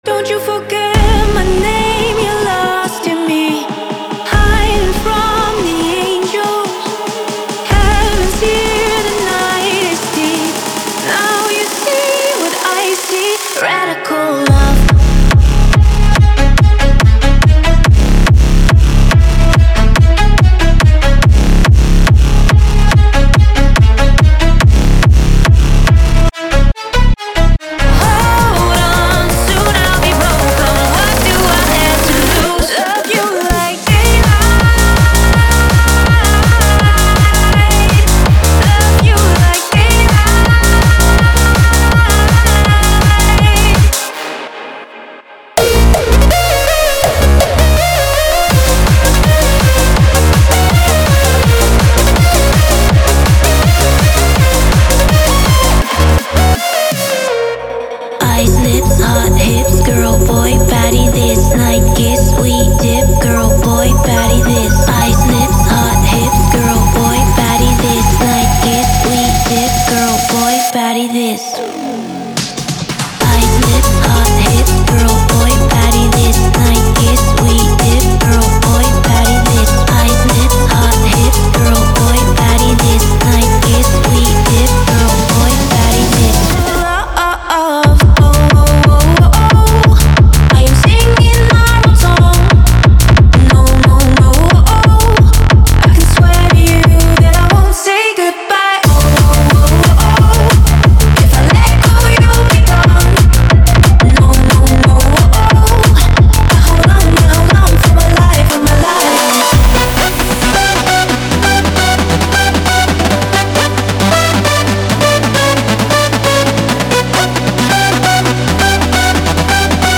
此外，超过 150 个女声循环和单次采样将为你的作品增添独特的人声魅力，进一步提升你的音色。